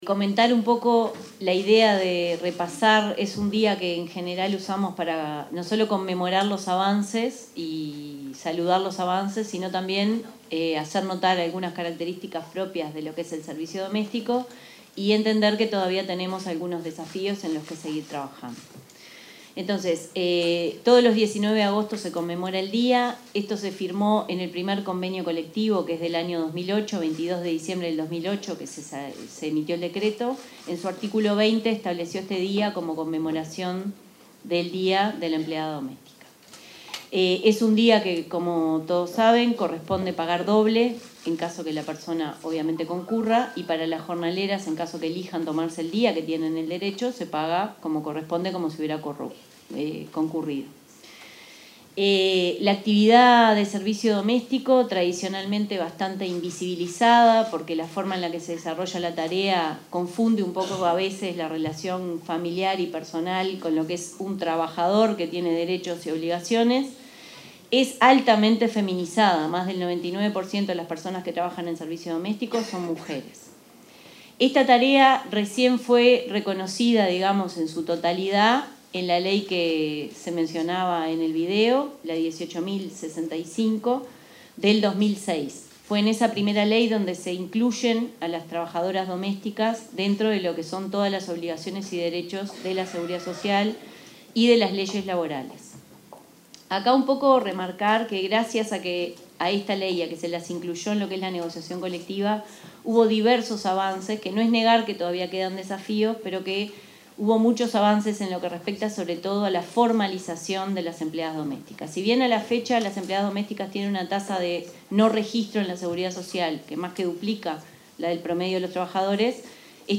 Palabras del ministro de Trabajo, Juan Castillo, y la presidenta del BPS, Jimena Pardo
oratoria.mp3